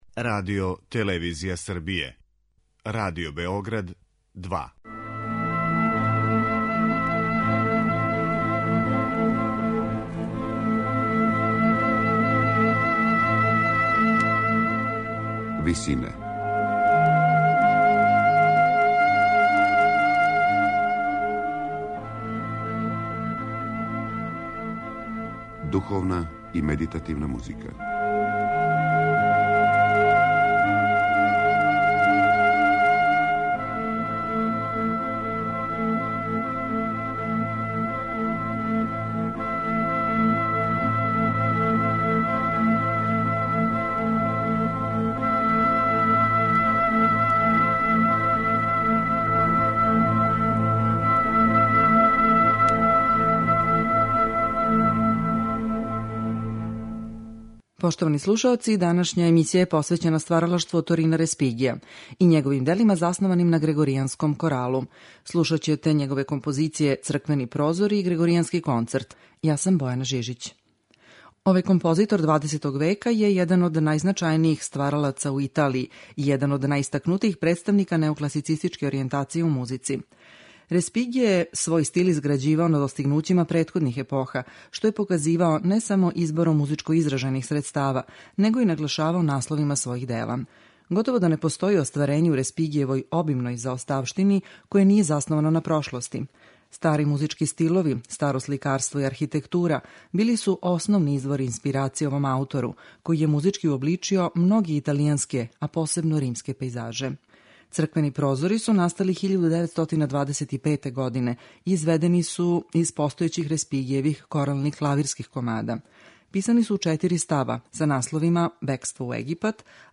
У данашњој емисији слушаћете Респигијеве композиције засноване на грегоријанском коралу: "Црквени прозори" и "Грегоријански концерт".
медитативне и духовне композиције